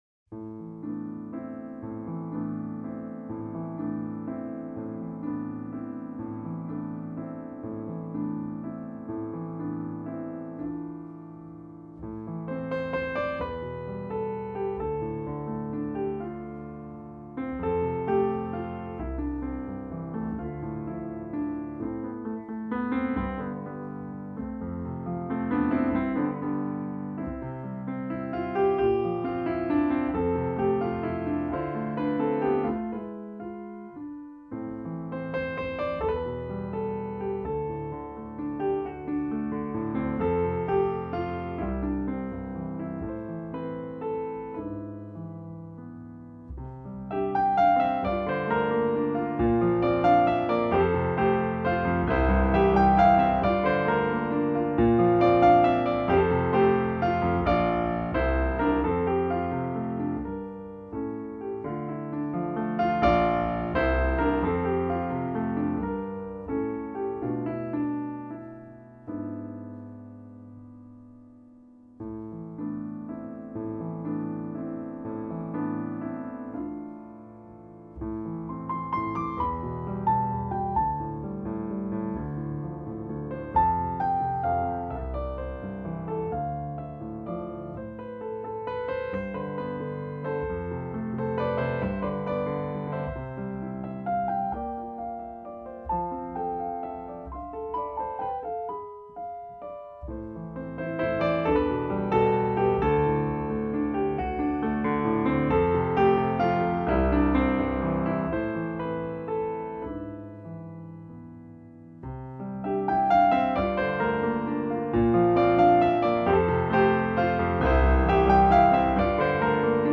pianoforte 'solo'